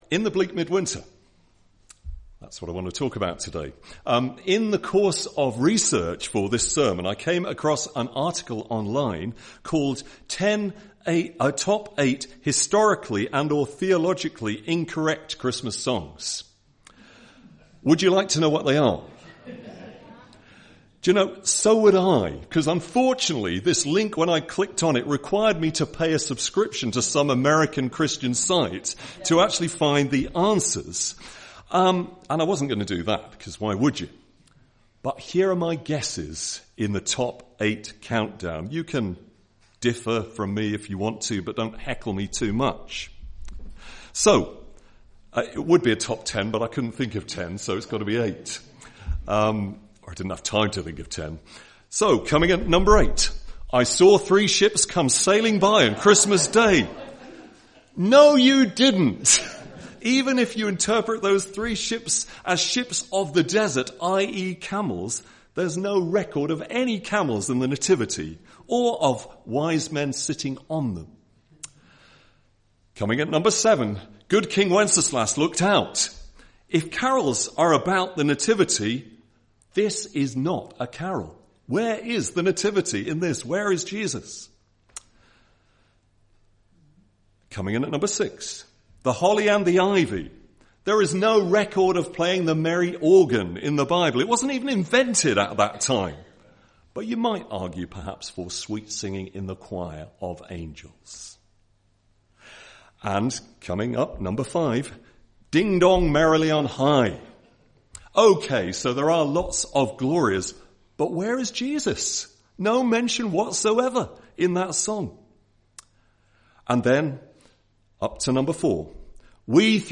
Sermon (Audio) - Well Street United Church